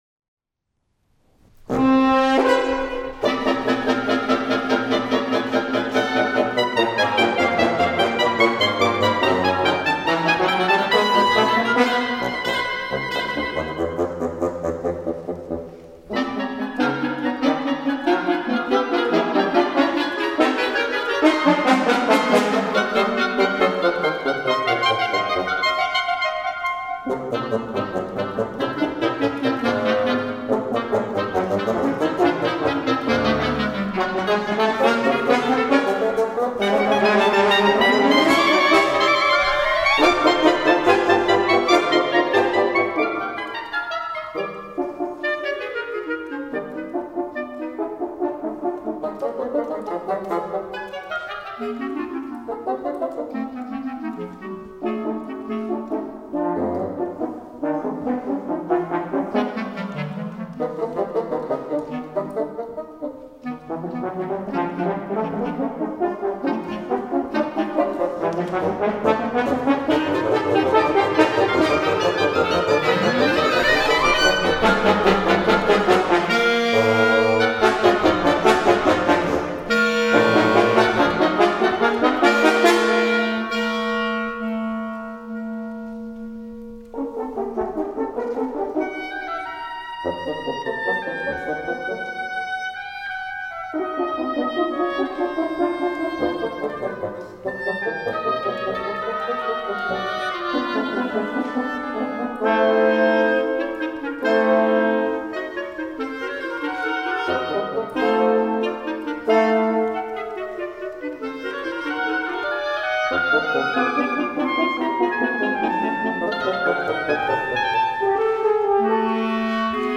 octet
The work straddles serious chamber music and divertimento, but with both elements jostling in most movements, much as in Mozart's great models.
Scoring: 2 oboes, 2 clarinets, 2 horns, 2 bassoons